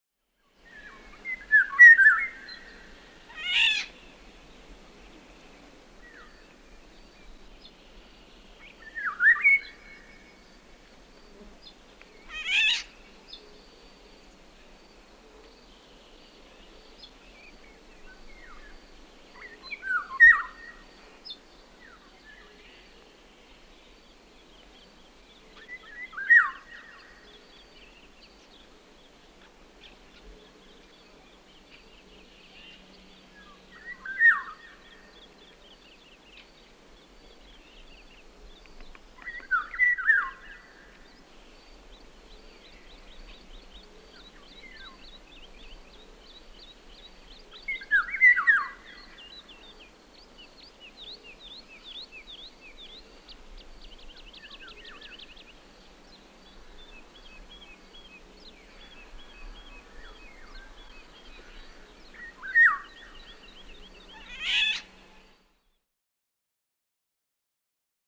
85164-Ivolga.mp3